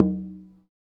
African Drum_04.wav